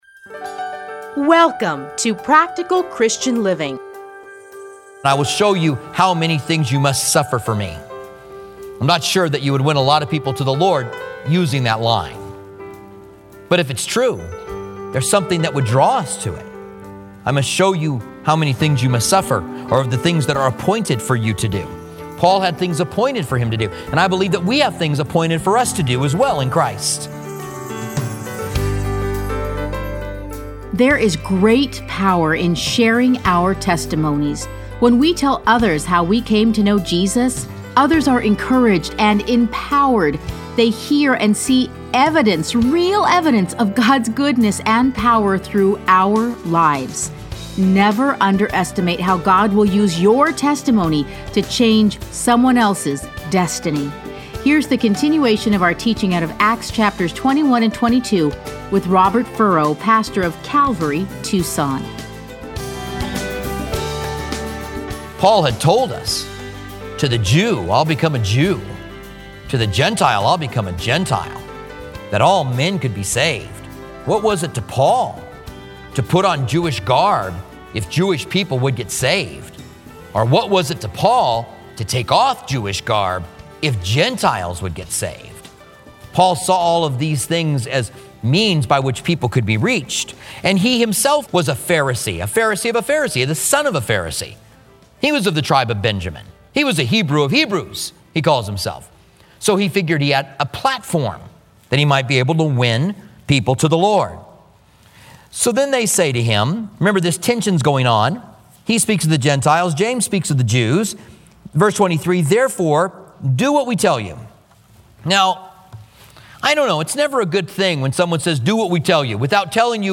Listen to a teaching from Acts 21 - 22; Playlists Commentary on Acts Download Audio